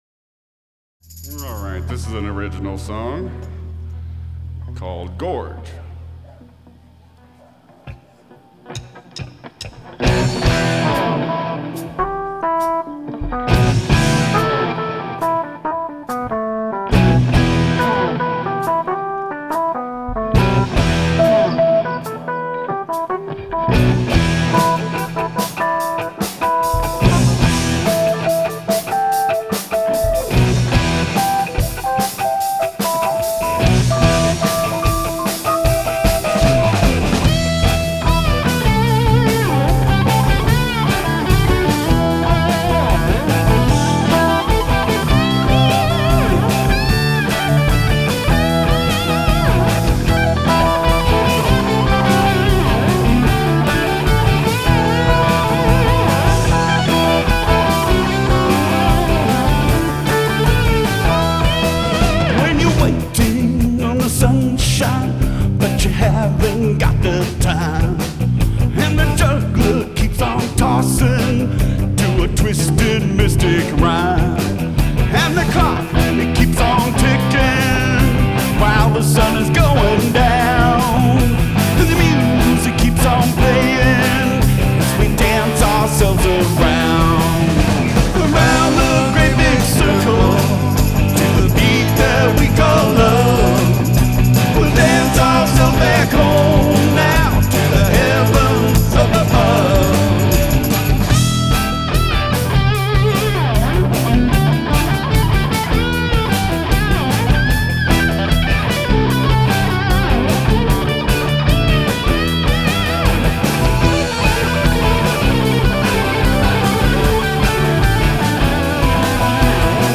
Seattle Peace Concert (Seattle) - 7/11/10